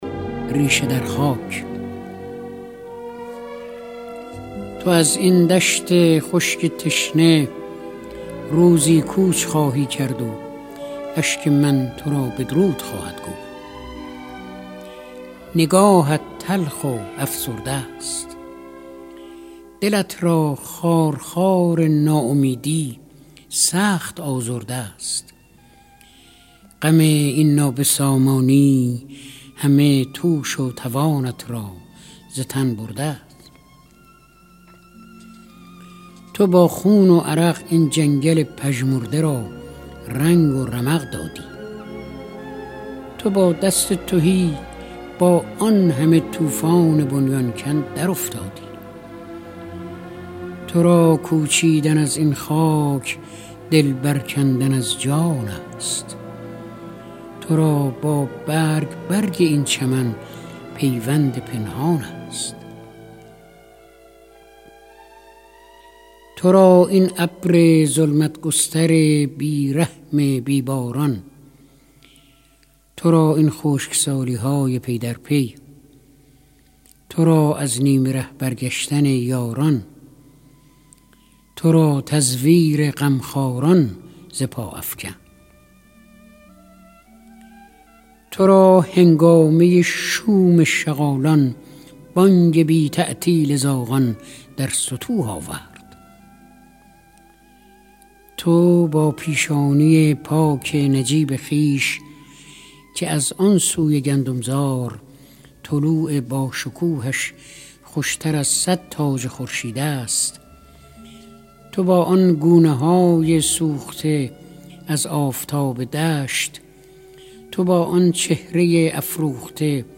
دانلود دکلمه ریشه درخاک با صدای فریدون مشیری با متن دکلمه
گوینده :   [فریدون مشیری]